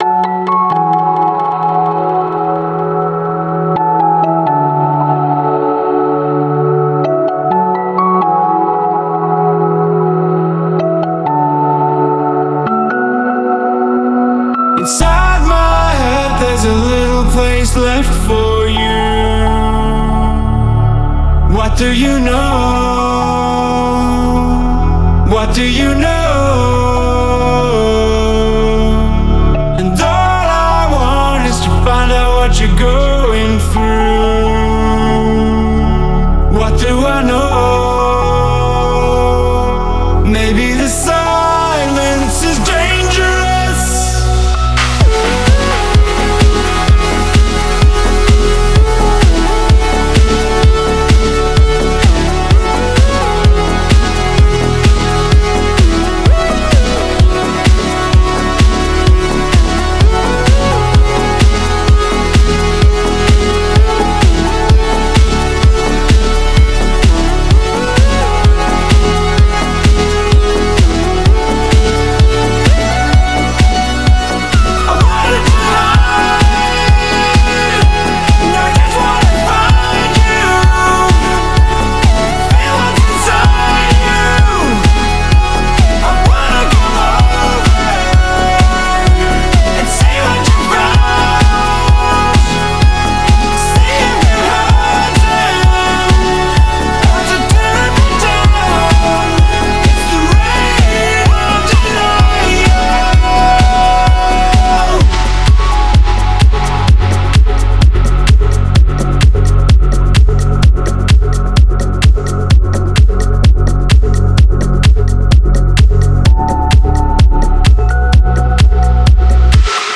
BPM128
Audio QualityMusic Cut
It's a nice progressive tune.